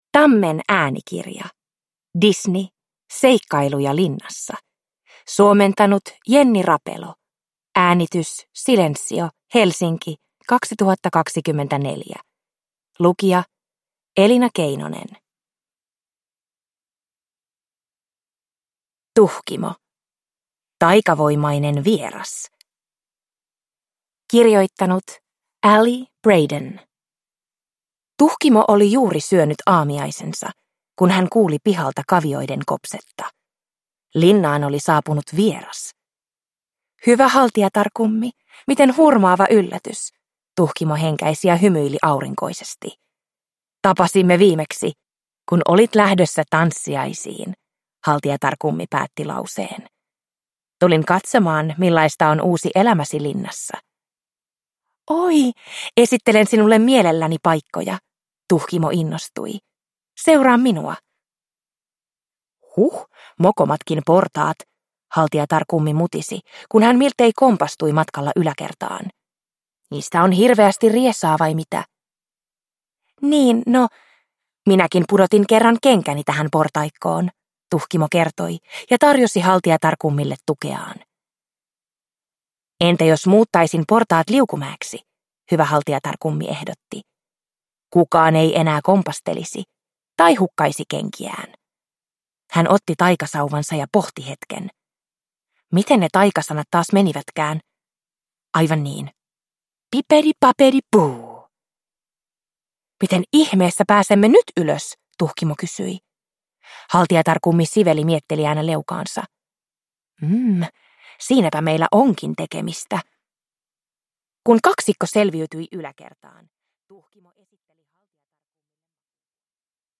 Disney. Prinsessat. Seikkailuja linnassa – Ljudbok